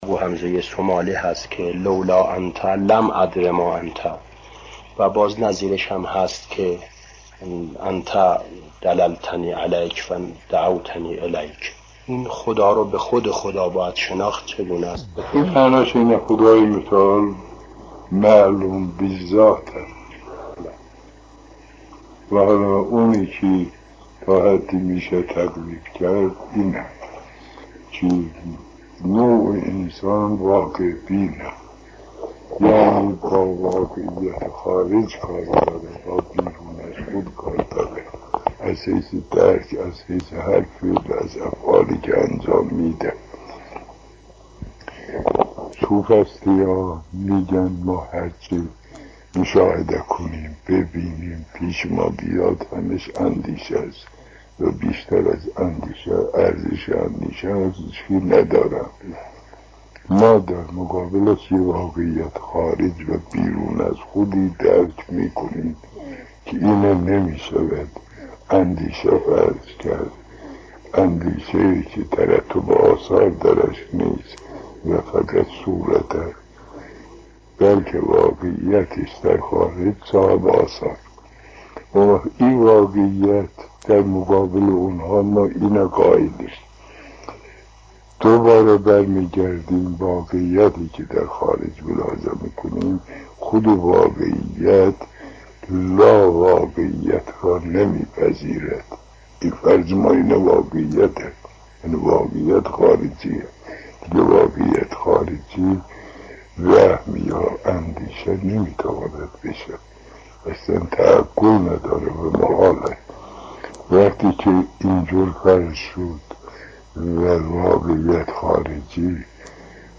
در این صوت مرحوم علامه طباطبایی با بیانی شرین و همه‌فهم به بیان برخی از احکام واقعیت پرداخته‌اند.